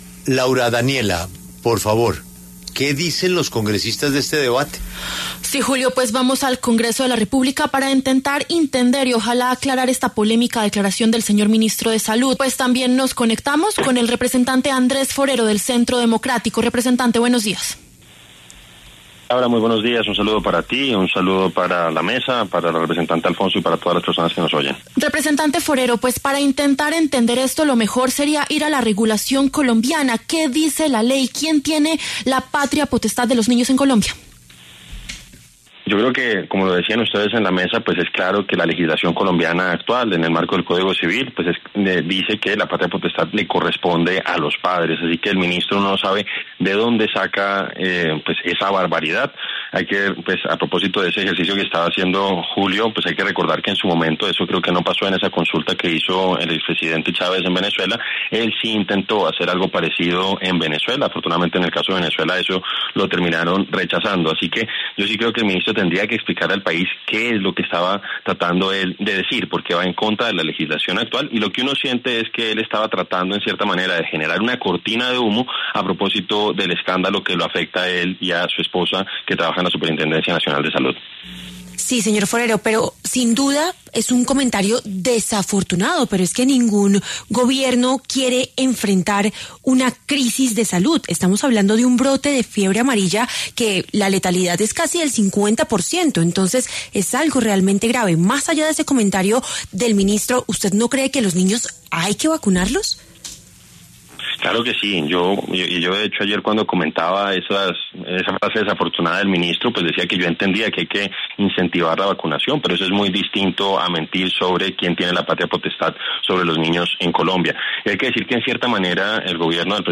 Andrés Forero, representante del Centro Democrático, habló con La W a propósito de las declaraciones del ministro de Salud, Guillermo Alfonso Jaramillo, sobre que la patria potestad de los niños es del Estado.